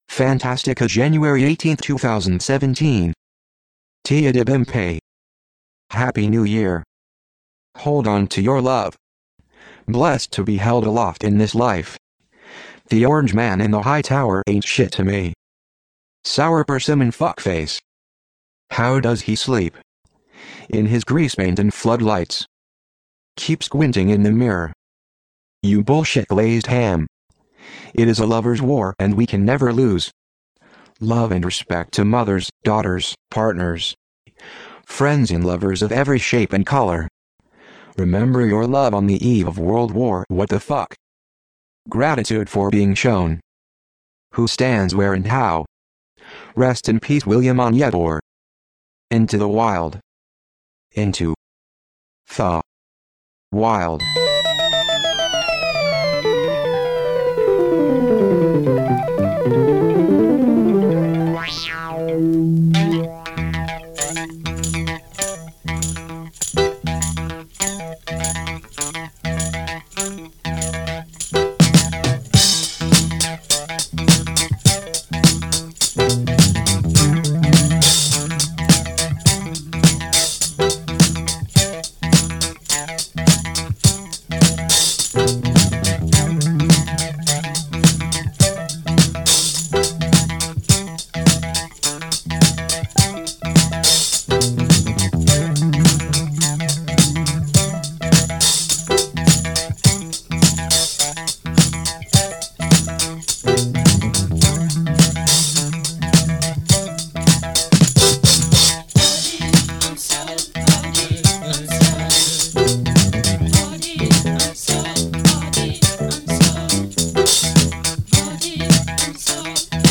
Every third Wednesday of the month I will be playing some of my old favorites and new discoveries music wise.